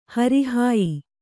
♪ hari hāyi